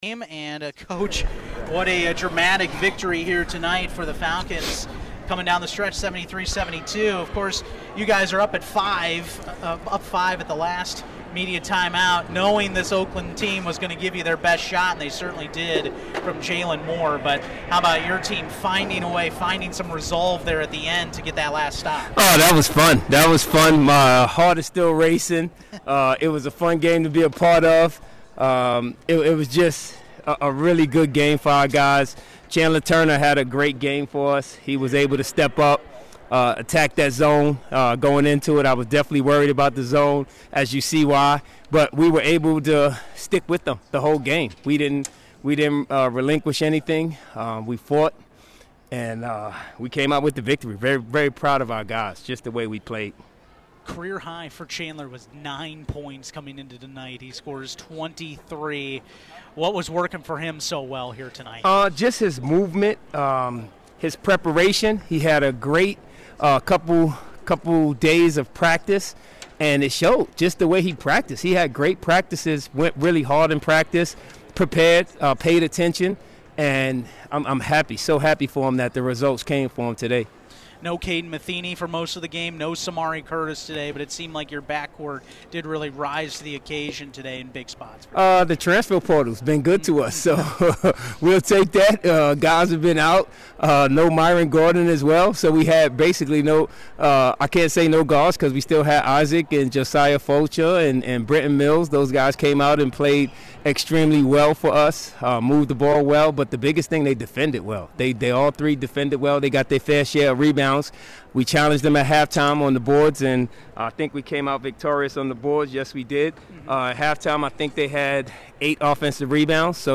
Full Postgame Interview
COACHES POSTGAME COMMENTS.mp3